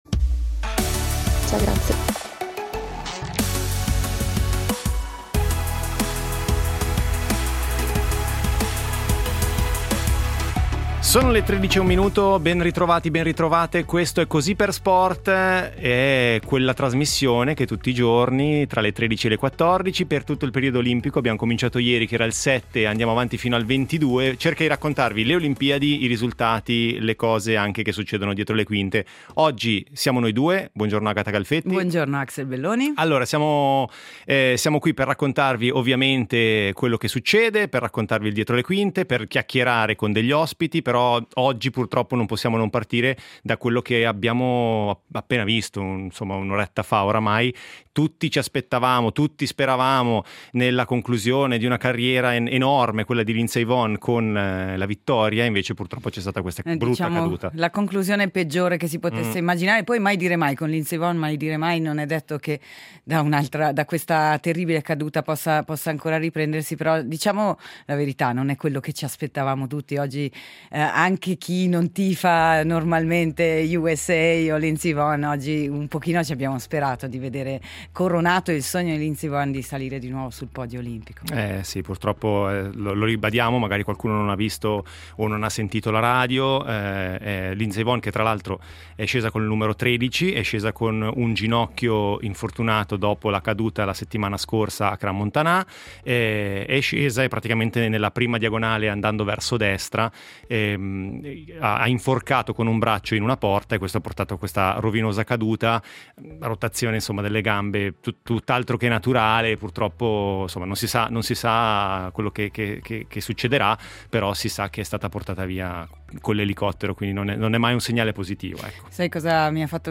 I risultati, il medagliere, le voci degli inviati e le storie degli ospiti che hanno vissuto sulla propria pelle la rassegna a cinque cerchi, ma anche i dietro le quinte e le curiosità delle varie discipline per immergerci tutti insieme – sportivi e meno – nello spirito olimpico.